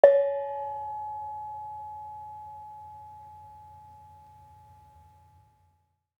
Bonang-C#4.wav